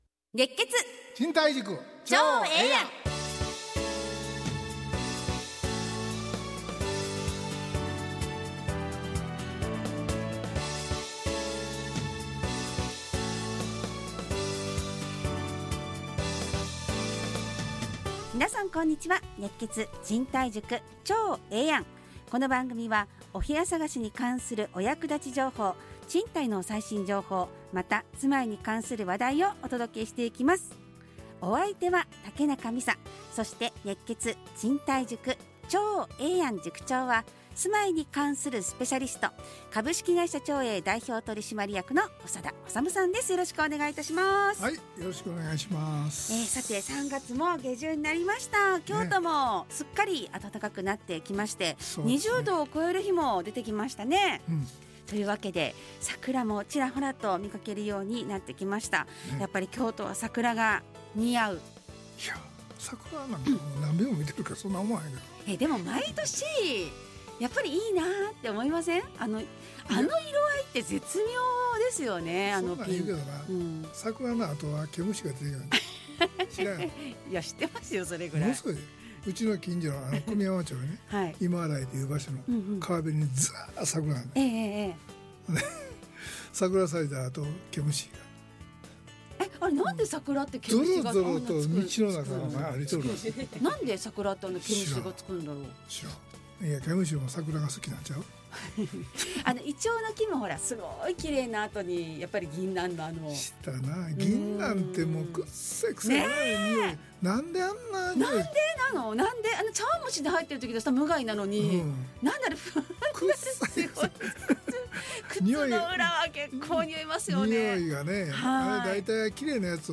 ラジオ放送 2025-03-28 熱血！